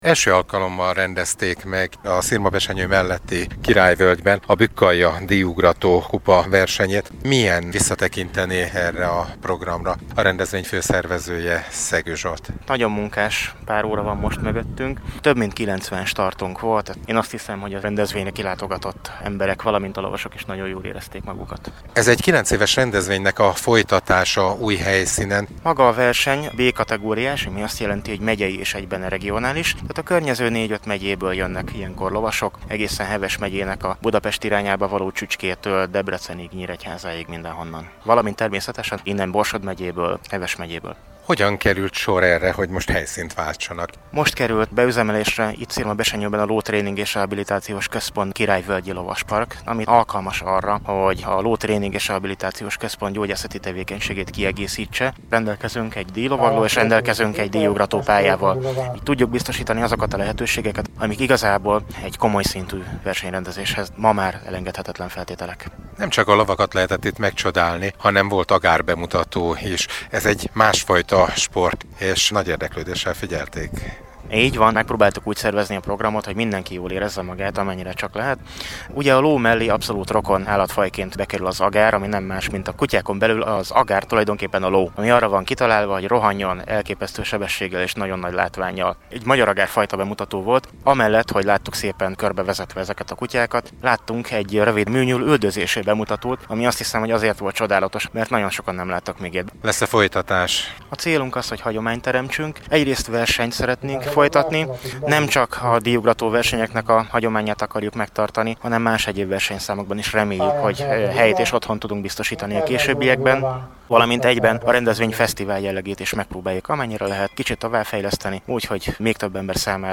A versenyről az MR6 Miskolci Stúdiója helyszíni tudósításban számolt be